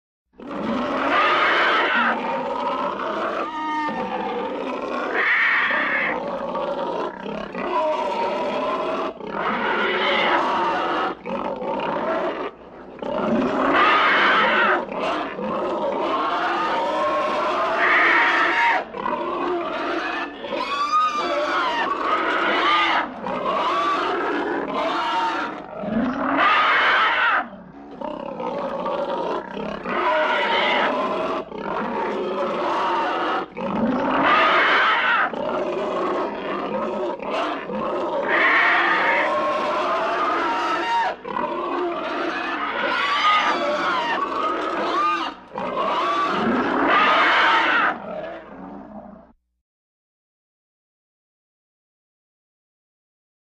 Борьба монстра с вампиром, крики, визги, рычания.
Borba_monstra_s_vampirom_kriki.mp3